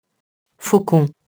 faucon [fokɔ̃]